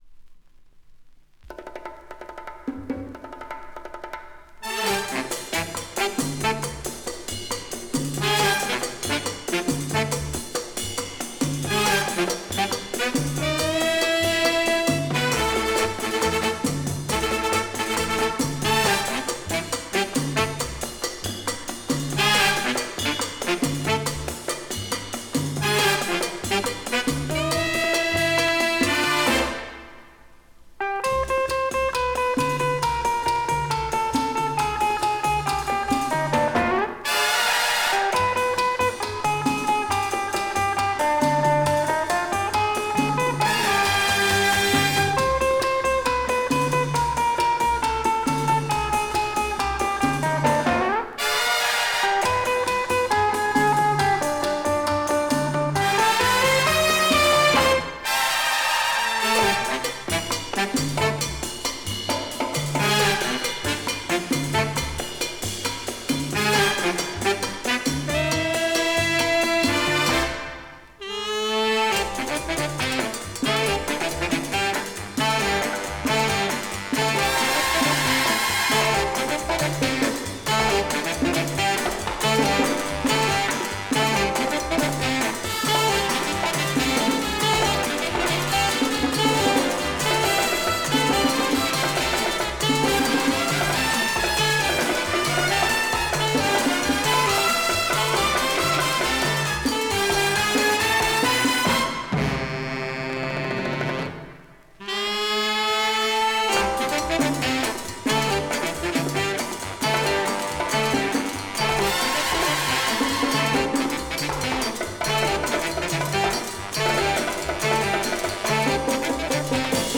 Жанр: Jazz, Latin, Pop, Easy Listening